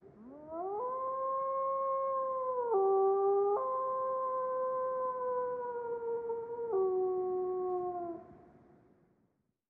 Здесь вы найдете реалистичные аудиозаписи воя, рычания и других эффектов, связанных с этими легендарными существами.
Звук воющего оборотня